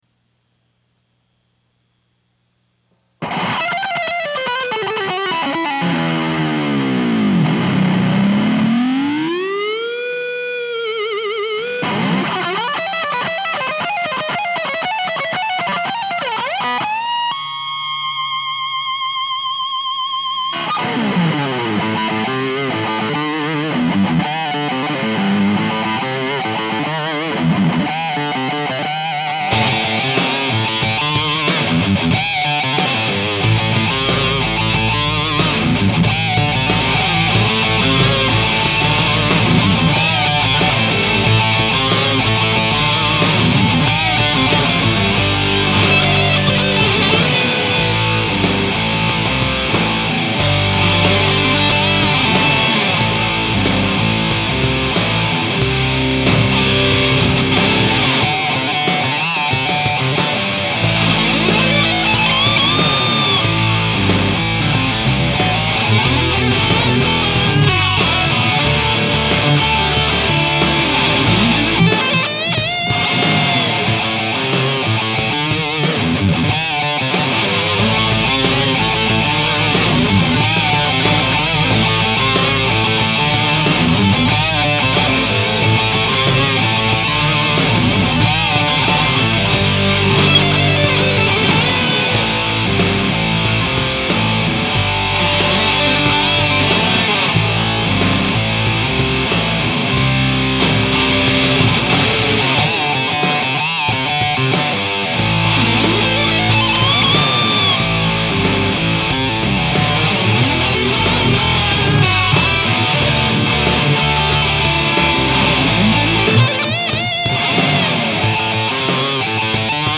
• Drums – Boss, Dr. Rhythm Drum Machine
• Recorded at the Park Springs Recording Studio